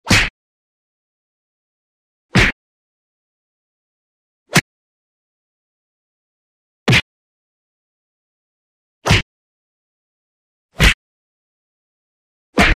Звуки пощечины
Шлепок по лицу в индийском кино